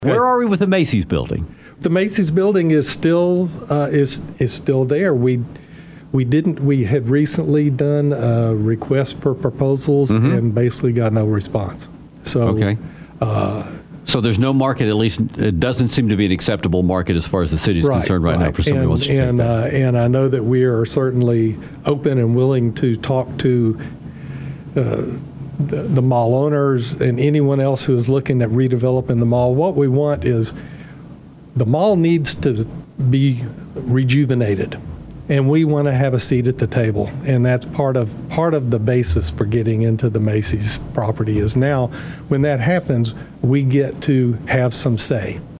Councilman Mark Smith, visiting on WTAW’s The Infomaniacs, says they received no offers to buy the building and surrounding parking area.